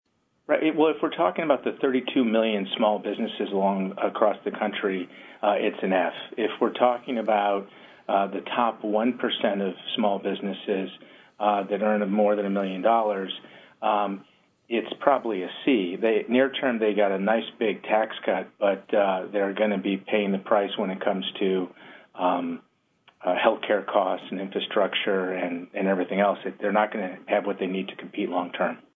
In-Depth Interview: Trump Is Bad for American Business, Says Small Biz Advocate